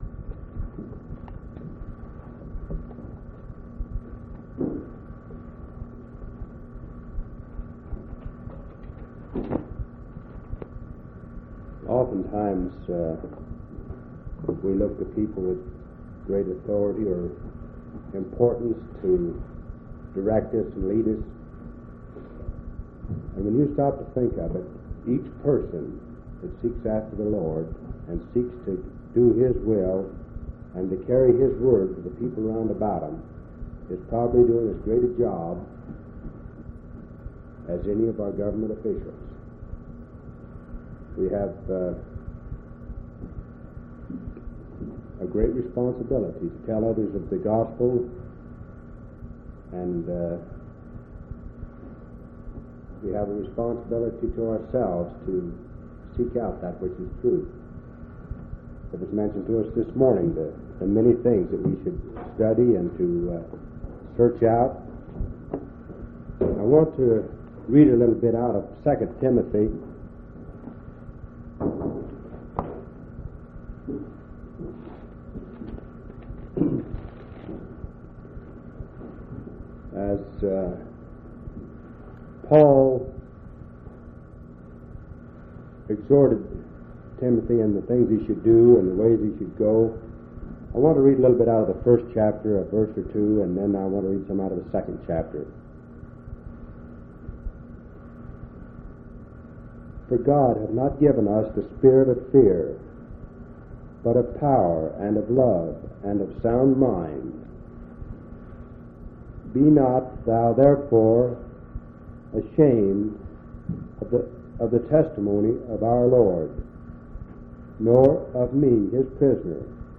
10/10/1976 Location: Grand Junction Local Event